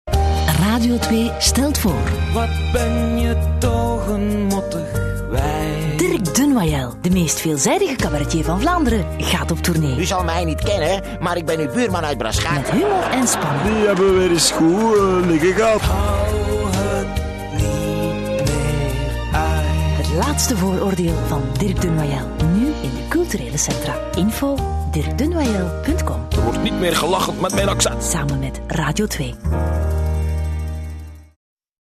De Eerste Versie van de Radio2 Trailer voor "Het laatste Vooroordeel": Tja, meer uitleg is hierbij overbodig !